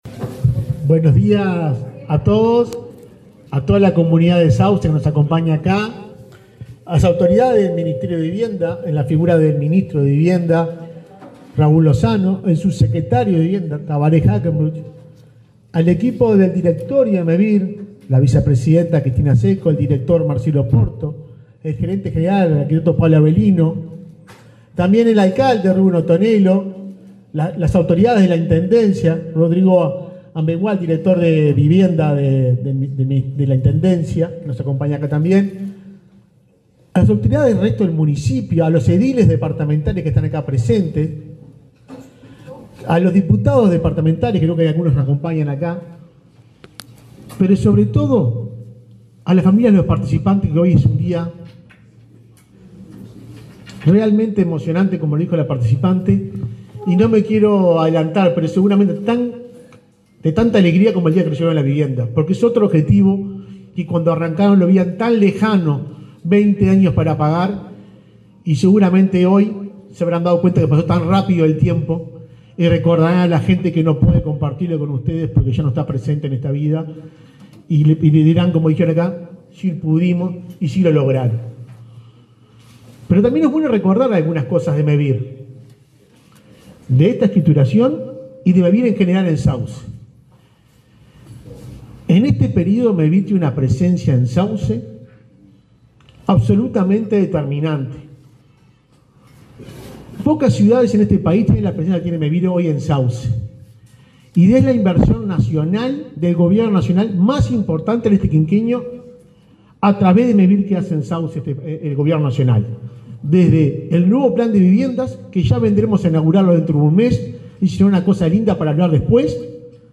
Palabras de autoridades en acto de Mevir en Sauce